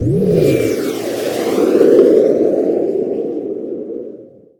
combat / aircraft / turn.ogg